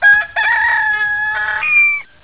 rooster14.wav